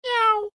AV_cat_short.ogg